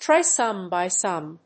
トライ・サム・バイ・サム